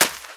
High Quality Footsteps
STEPS Sand, Run 08.wav